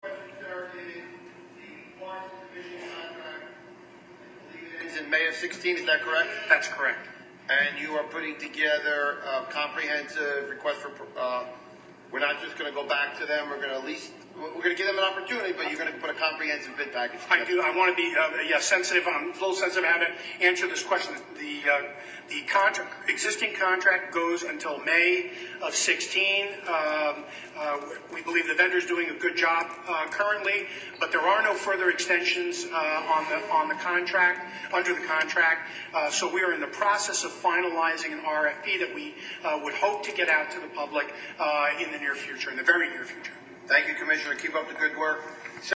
I was able to isolate a couple of clips from the hearing which address these issues of particular importance to you.
First, you will hear Chairman Sarlo ask Chief Administrator Martinez about the expiration of the current inspection contract with Parsons, and the Request for Proposals for a new company to administer the inspection program.  The Chief explains pretty much what I have been saying all along, that they are in the process of finalizing an RFP that they hope to get out to the public in the "very near future."